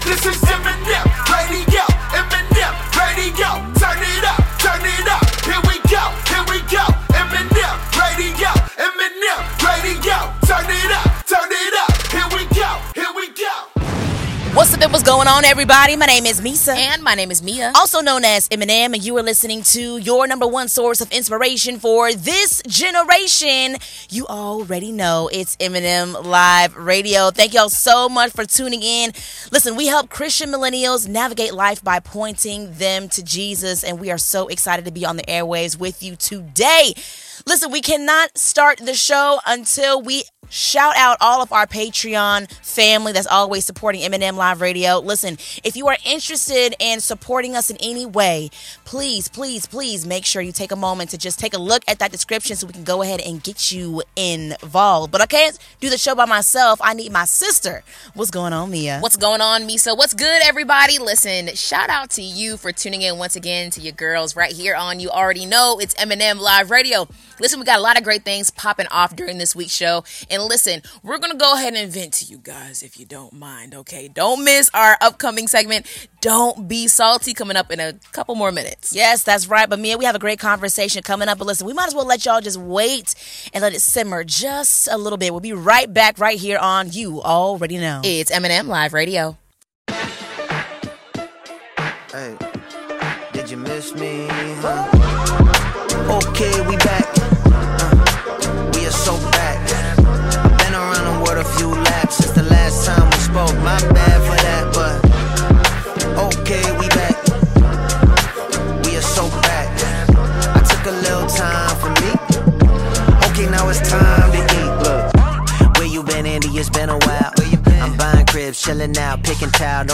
Through inspiring music, powerful stories, and thought-provoking interviews, this is the show where faith meets culture—all to point you back to Jesus.